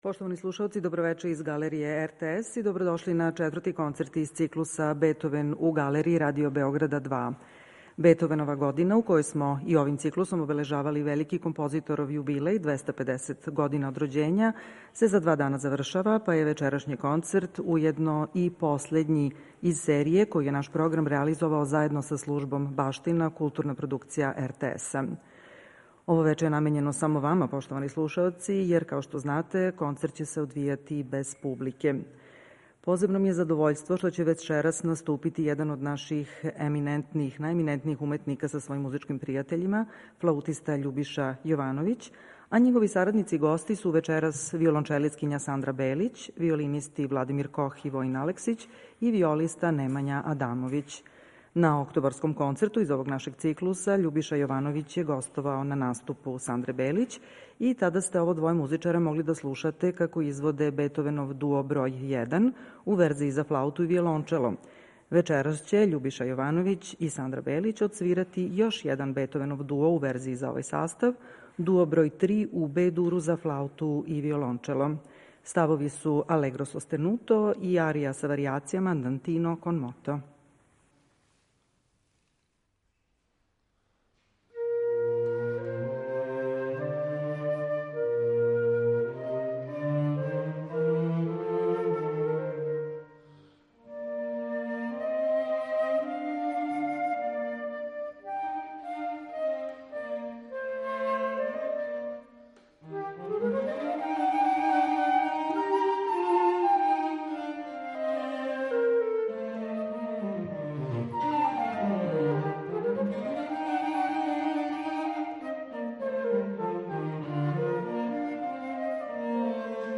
Директан пренос концерта можете пратити на таласима Радио Bеограда 2 и на платформи РТС Планета.
за флауту и виолончело
за флауту и гудачки квартет